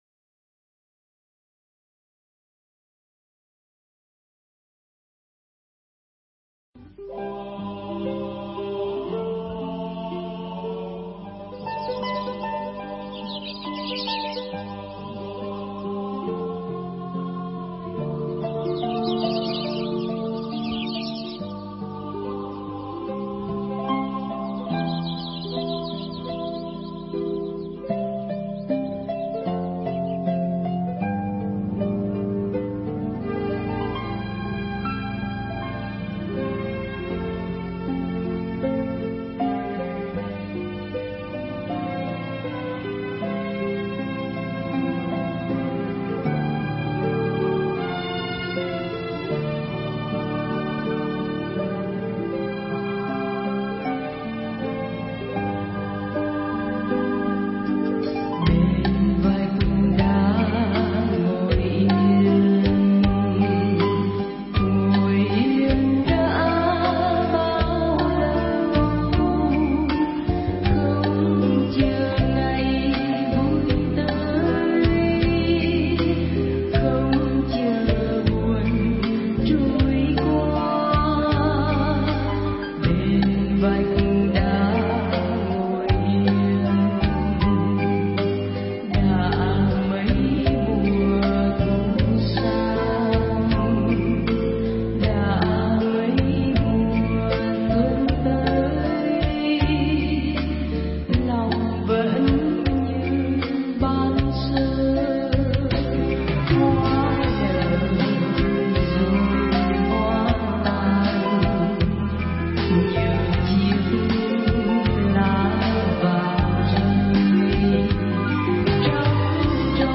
Mp3 Thuyết Giảng Mục Đích Tọa Thiền – Hòa Thượng Thích Thanh Từ giảng tại Thiền Viện Trúc Lâm, Đà Lạt, ngày 10 tháng 10 năm 2005, (ngày 8 tháng 9 năm Ất Dậu)